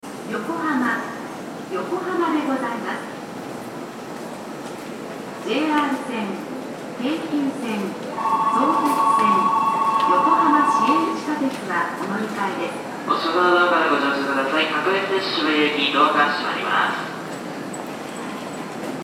横浜駅　Yokohama Station ◆スピーカー：National天井型
2番線発車ベル